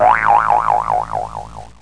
SFX头晕晕倒下载音效下载